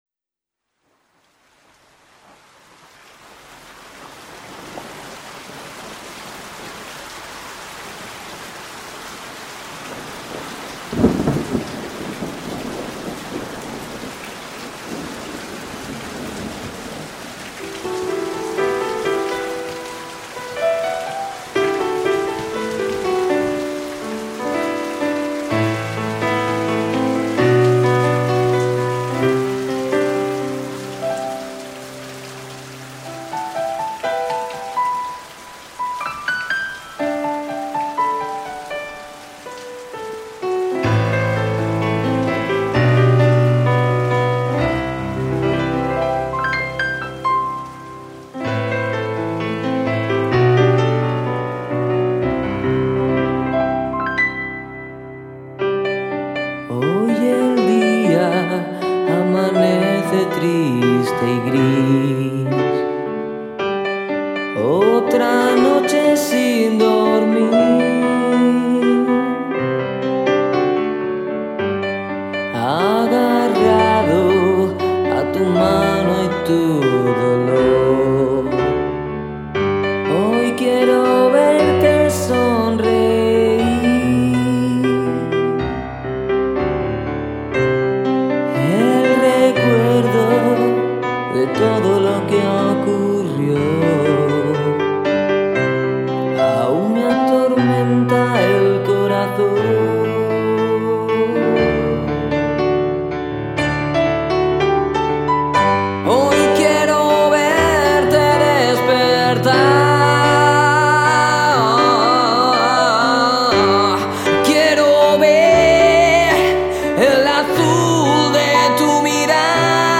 Versión Piano